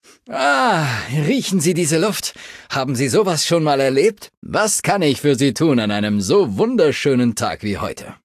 Datei:Maleadult01default dialogueandale greeting 0002e9df.ogg
Fallout 3: Audiodialoge